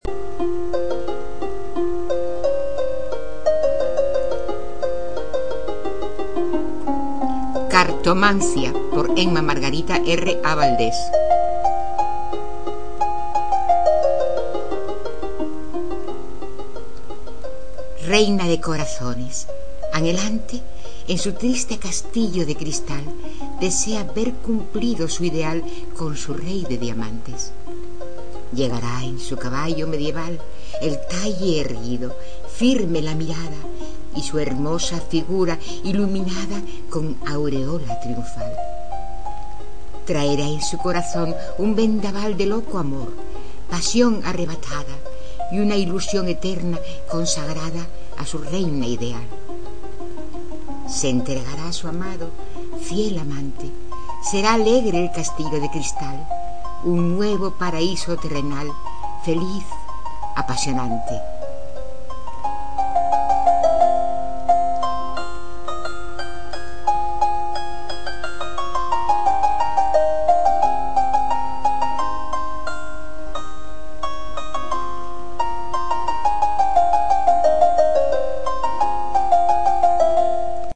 mp3, recitada por la autora.